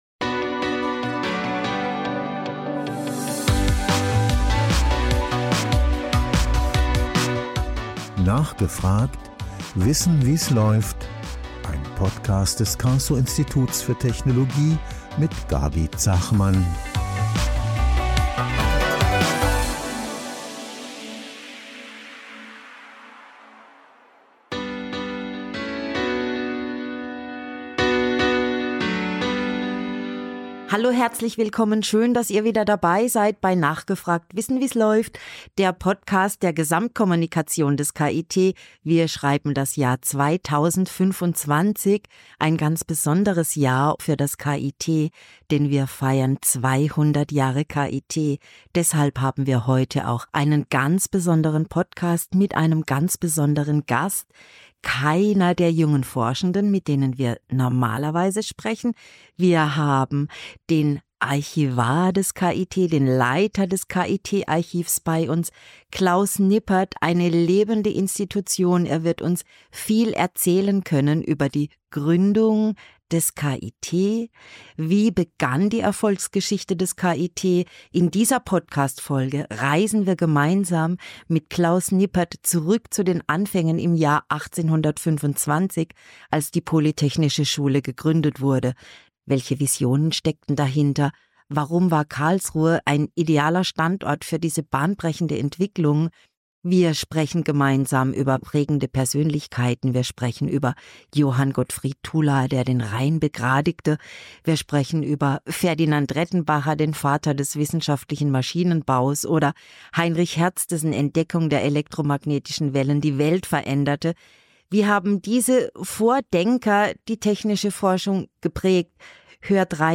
Diese Folge geht auf eine Zeitreise durch die turbulente Geschichte der Universität Karlsruhe – von dampfbetriebenen Visionen bis zu Lasern und Quantencomputern. Im Interview-Podcast des Karlsruher Instituts für Technologie (KIT) sprechen unsere Moderatorinnen und Moderatoren mit jungen Forschenden, die für ihr Thema brennen.